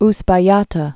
(spä-yätə, -tä)